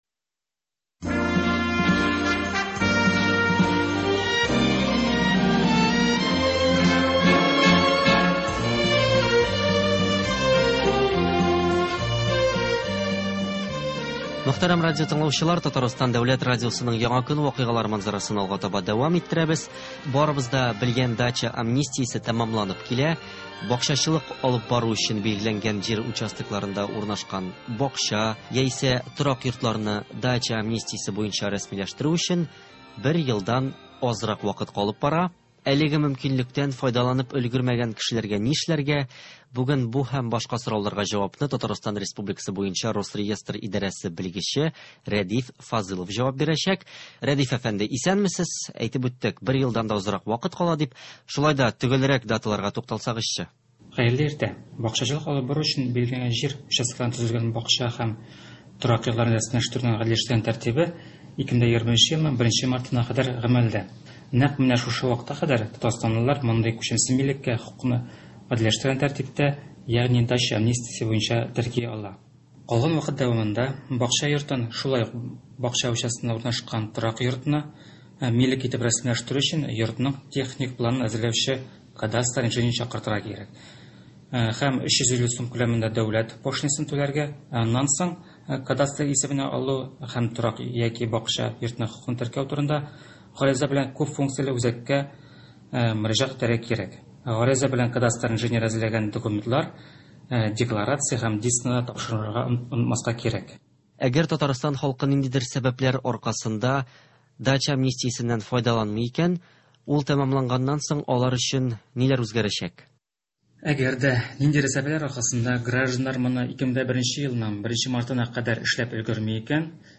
“Актуаль интервью”. 6 июль.